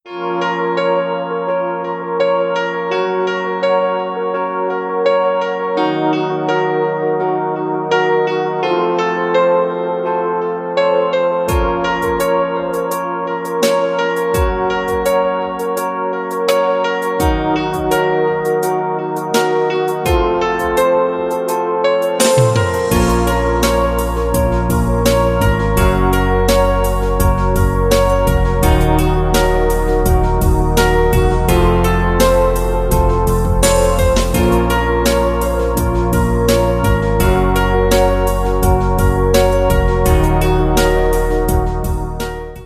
Рингтон Красивая музыка на пианино